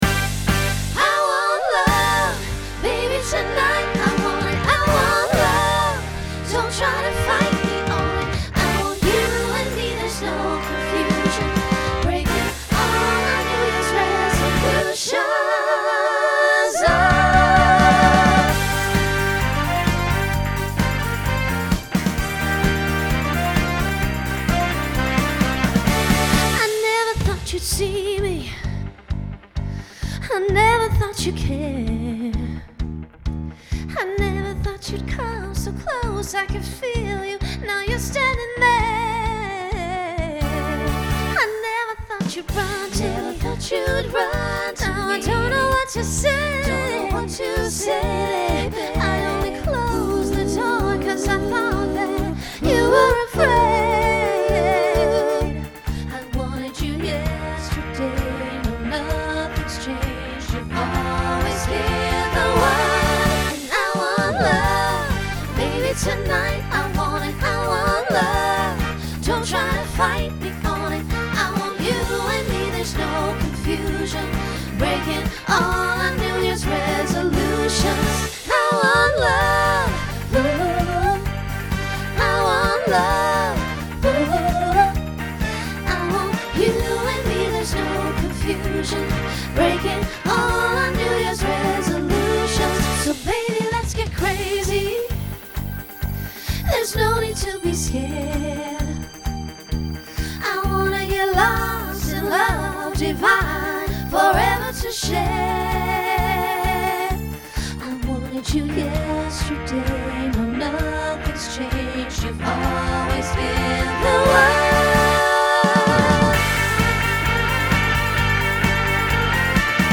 Pop/Dance Instrumental combo
Opener Voicing SSA